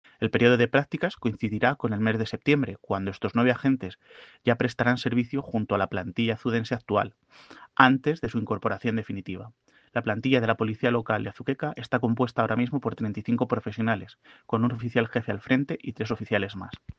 Declaraciones del alcalde sobre las prácticas y plantilla